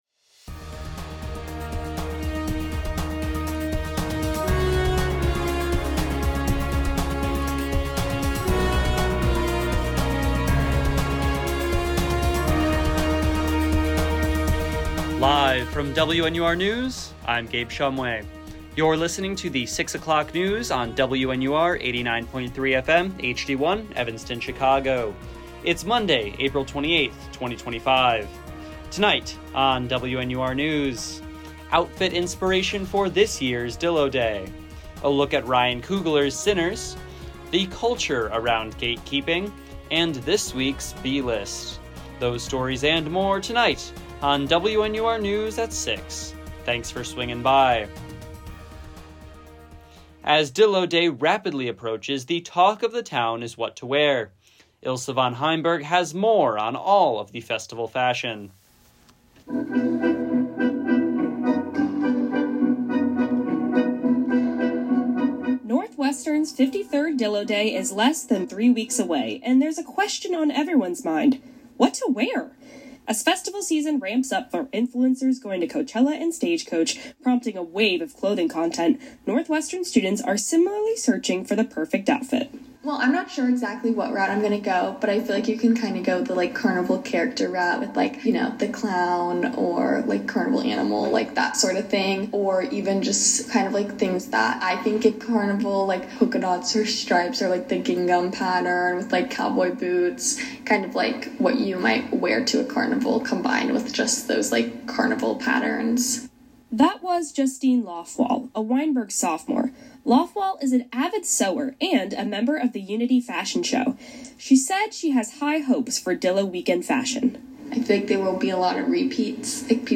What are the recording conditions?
April 28, 2025 OUTFIT INSPIRATION FOR THIS YEAR’S DILLO DAY, A LOOK AT RYAN COOGLER’S SINNERS, THE CULTURE AROUND GATEKEEPING, AND THIS WEEK’S B-LIST WNUR News broadcasts live at 6 pm CST on Mondays, Wednesdays, and Fridays on WNUR 89.3 FM.